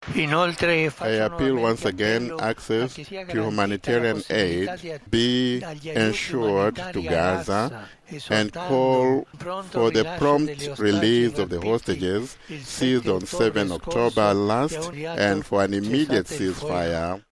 The Pope has called for a Gaza ceasefire – during Easter Sunday Mass at The Vatican.
The 87 year old looked well, as he addressed thousands who had gathered at St Peter’s Square.